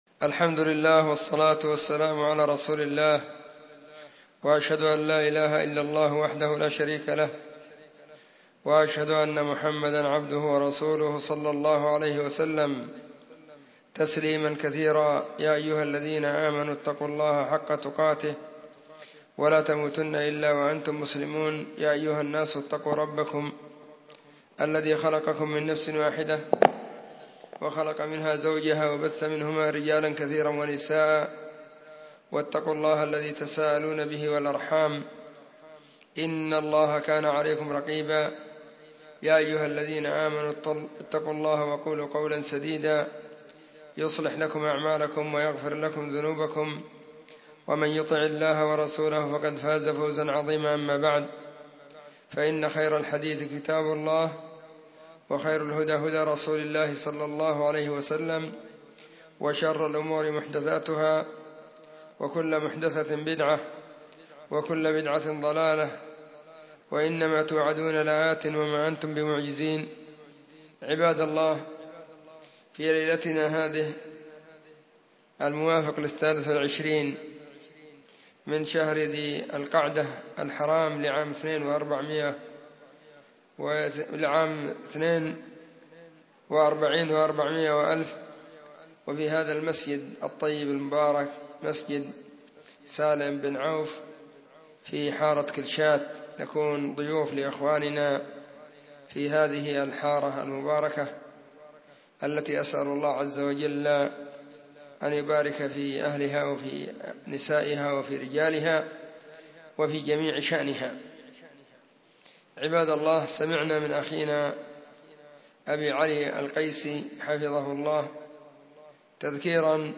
محاضرة بعنوان; *📚صلاح الآخرة بصلاح الدين.*
📢 مسجد الصحابة – بالغيضة – المهرة، اليمن حرسها •اللّـہ.